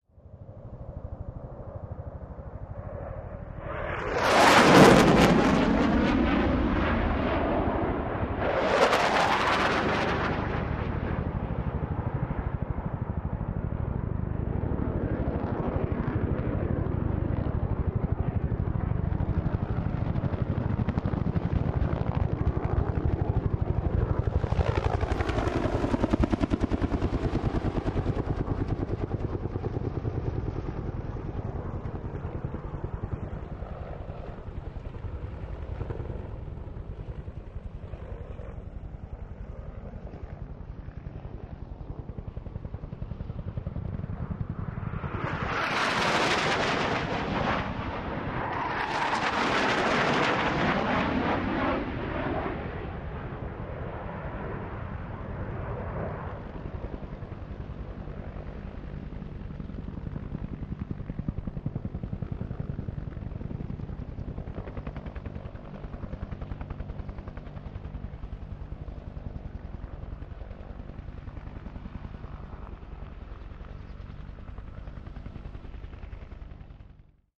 Multiple jets and helicopters fly by. Invasion, Air War, Explosion Explode, Bomb